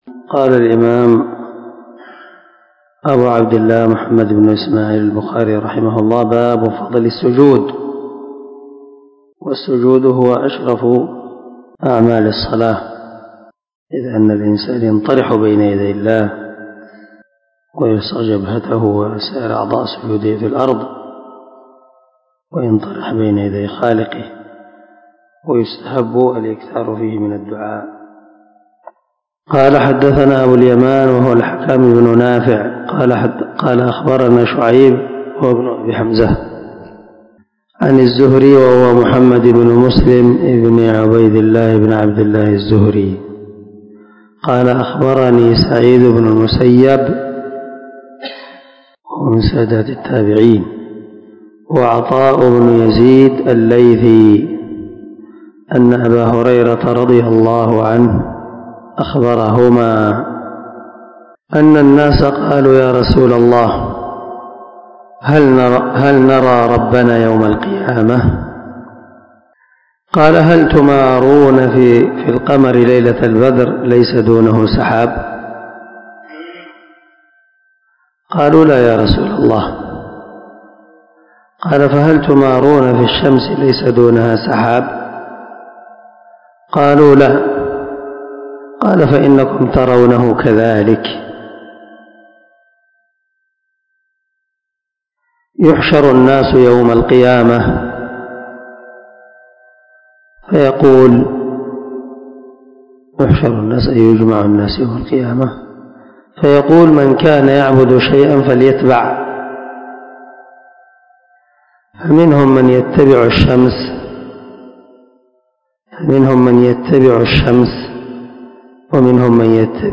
518الدرس 101 من شرح كتاب الأذان حديث رقم ( 806 ) من صحيح البخاري
دار الحديث- المَحاوِلة- الصبيحة.